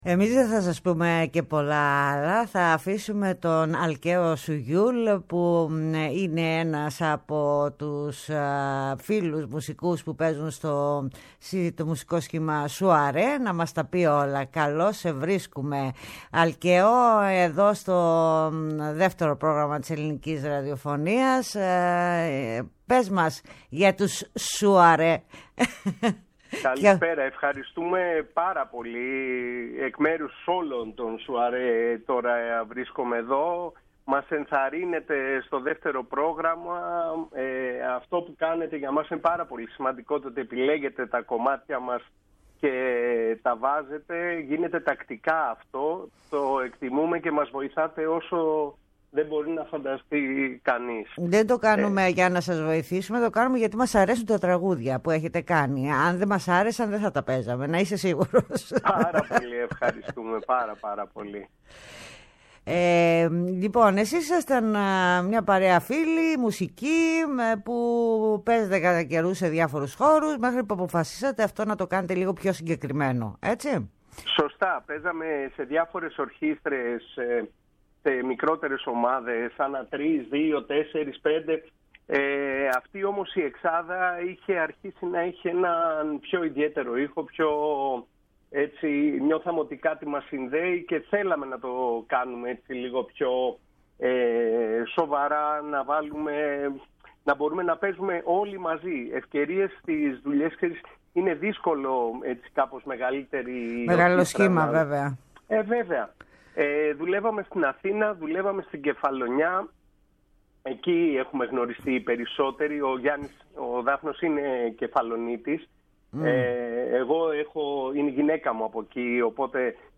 συνομιλεί